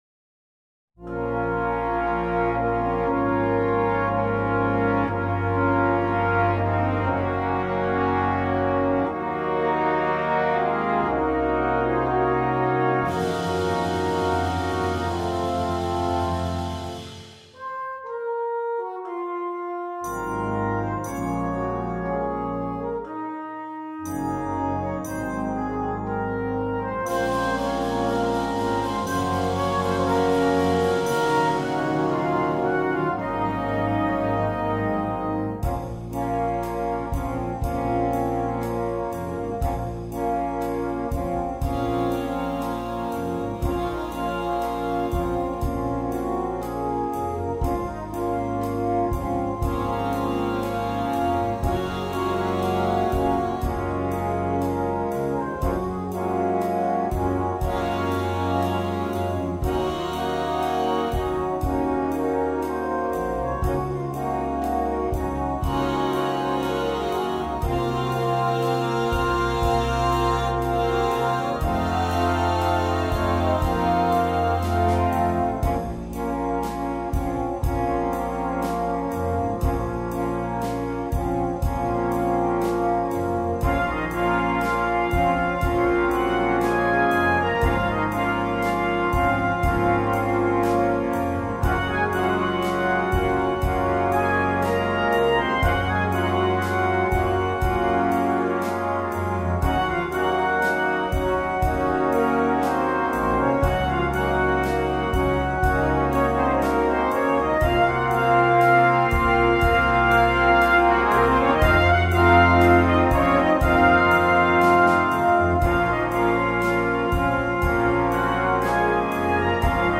2. Brass Band
sans instrument solo
Musique légère
facile